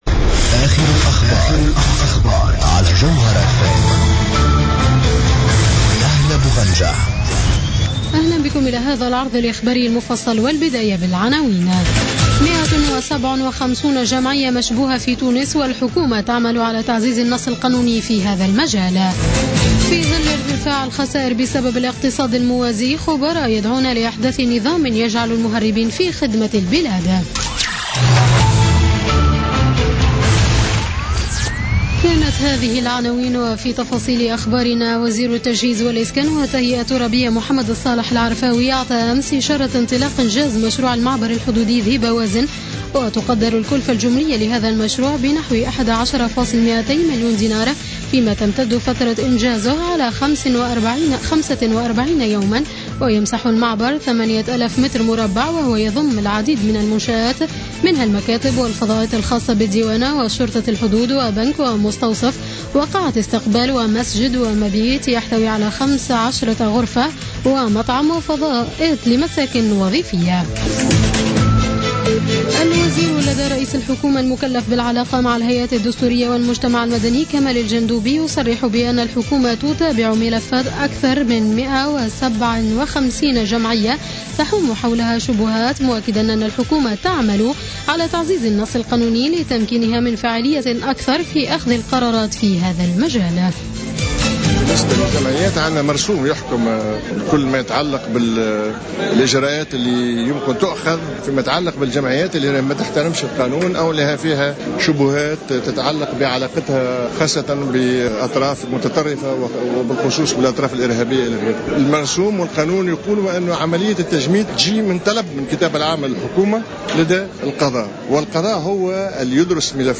نشرة أخبار منتصف الليل ليوم الأحد 24 أفريل 2016